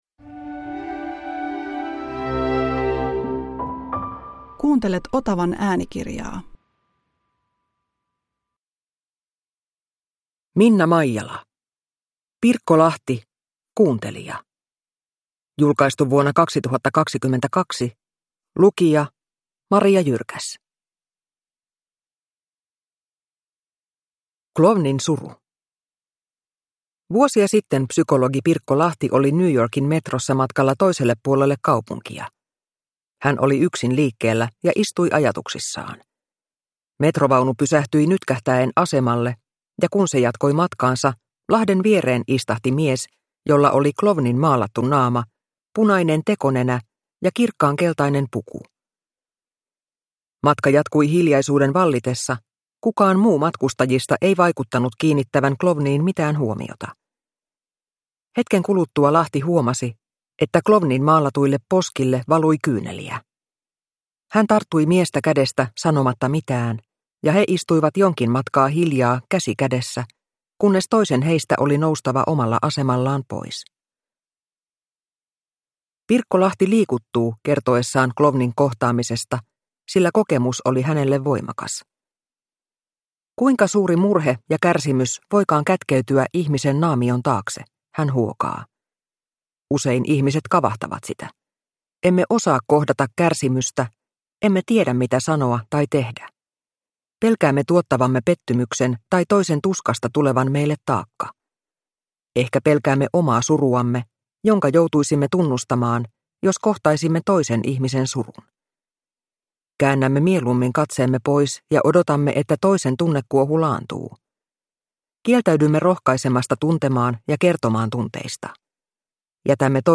Pirkko Lahti – Ljudbok